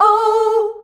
OUUH  A.wav